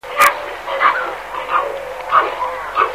Pelikan kędzierzawy - Pelecanus crispus
głosy